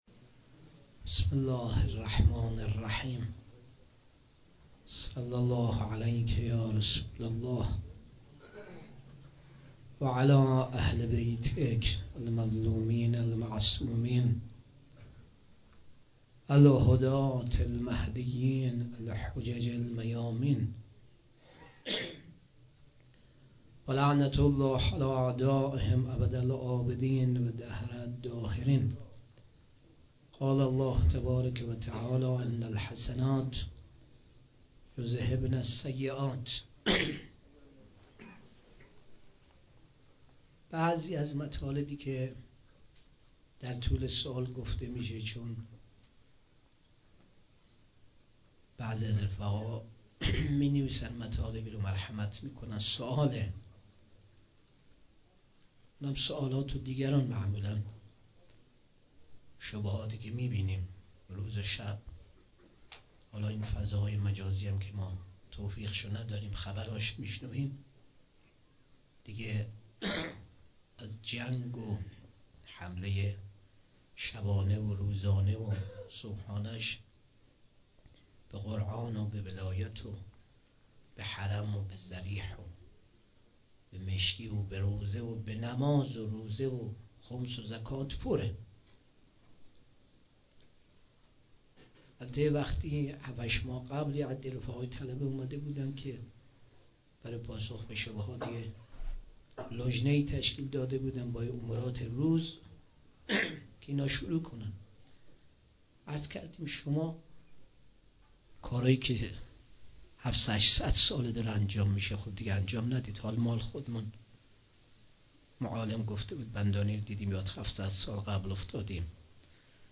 2 صفر 96 - غمخانه بی بی شهربانو - سخنرانی